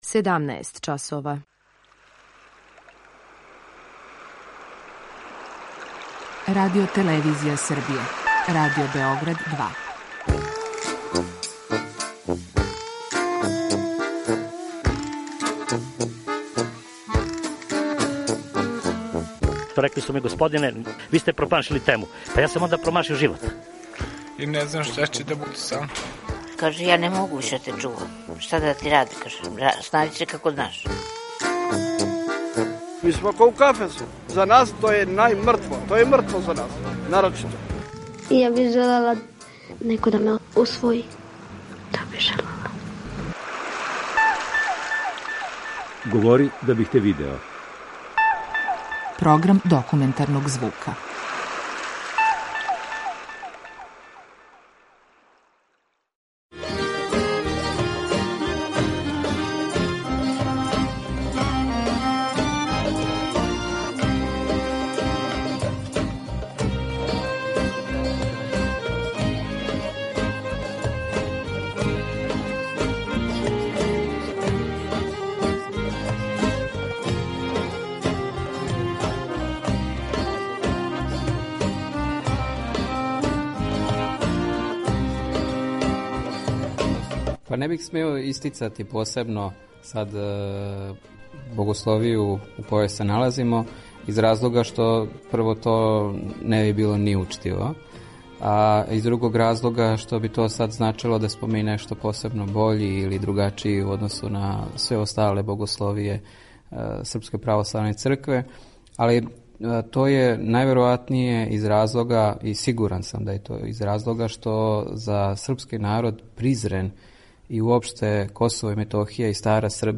Документарни програм
*Музику коју сте слушали током емисије изводи Хор и оркестар Призренске богословије.